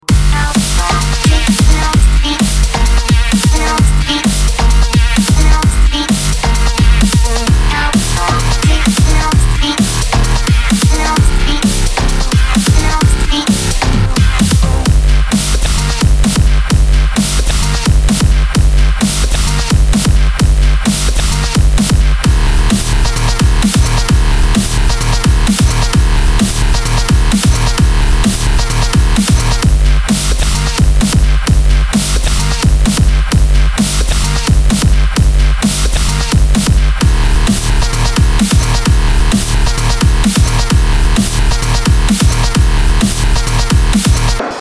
• Качество: 320, Stereo
жесткие
мощные
без слов